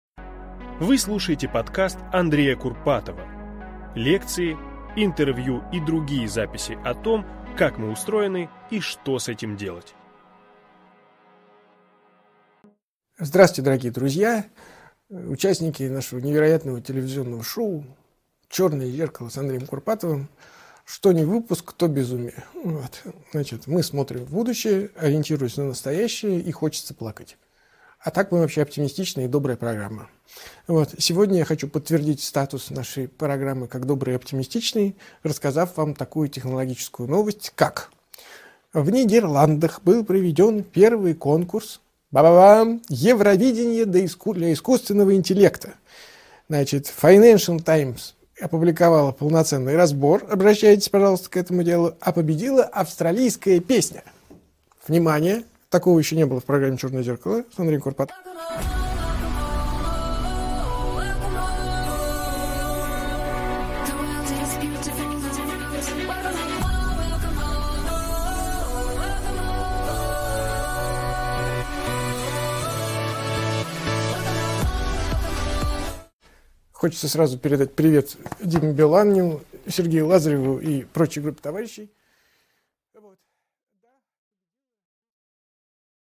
Аудиокнига Евровидение и искусственный интеллект. Черное зеркало с Андреем Курпатовым | Библиотека аудиокниг